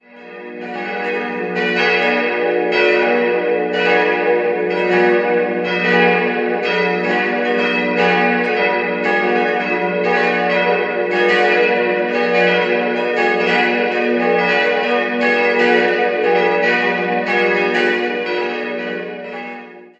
4-stimmiges Geläute: fis'-gis'-h'-dis'' Die kleinste Glocke stammt von Hans Pfeffer (Nürnberg) und wurde 1617 gegossen.